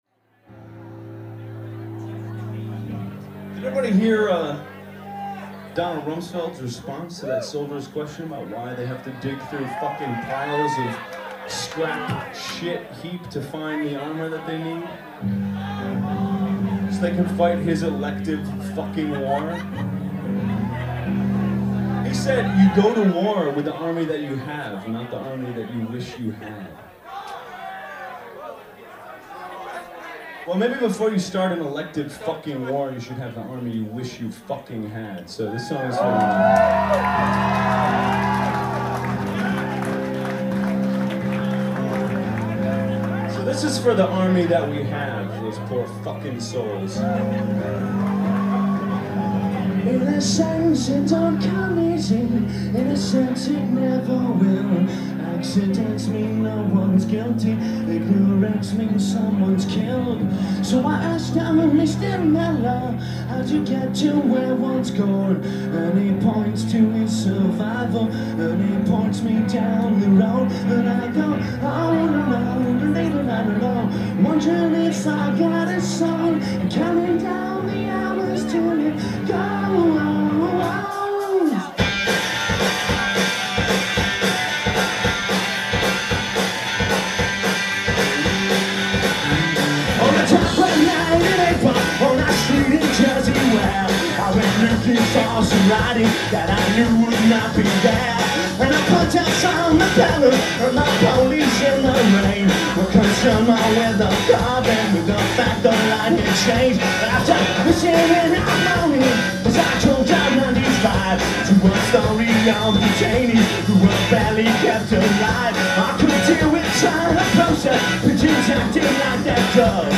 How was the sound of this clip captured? live at the Middle East, Cambridge, MA